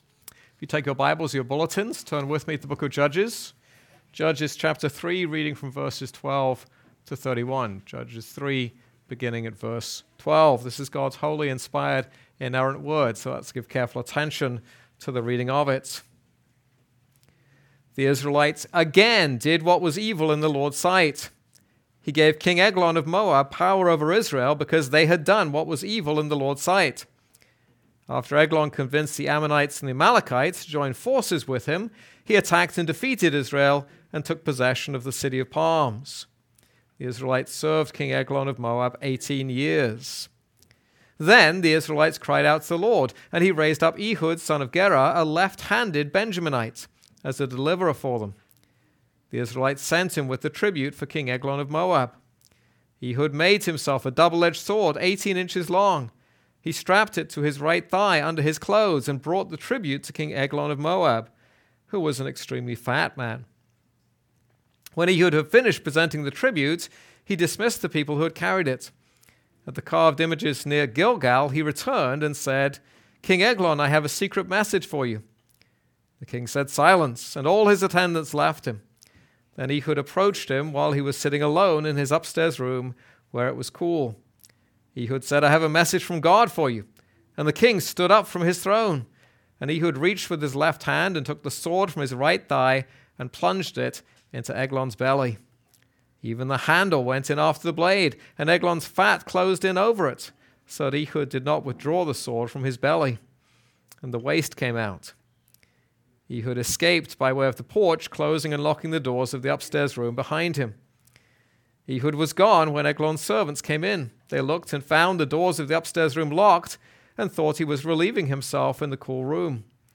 This is a sermon on Judges 3:12-31.